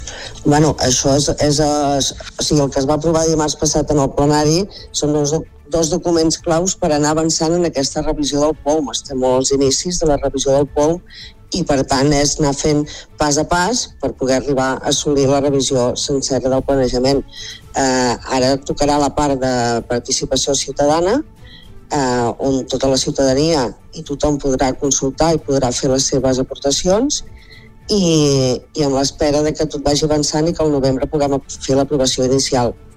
Supermatí - entrevistes
I per parlar de la organització d’aquest POUM i d’aquesta aprovació hem parlat al Supermatí amb l’alcaldessa de Begur i Esclanyà, Maite Selva.